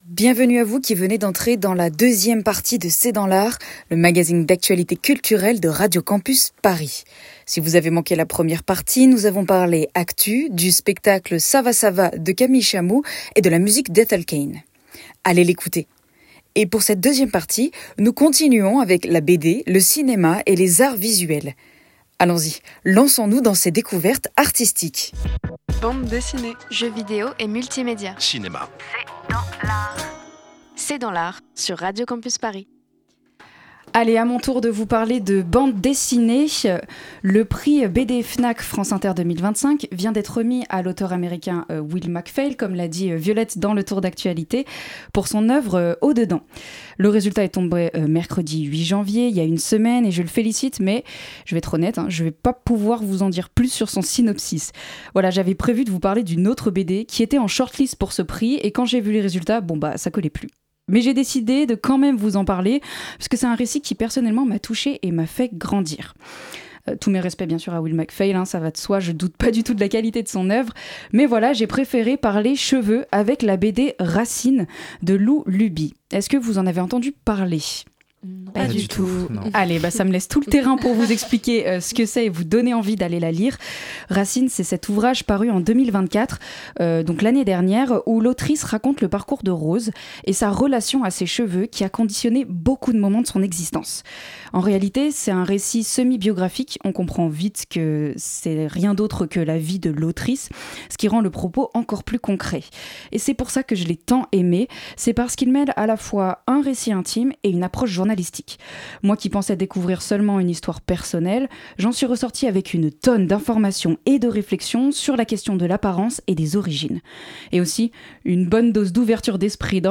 C'est dans l'art, c'est la nouvelle émission d'actualité culturelle de Radio Campus Paris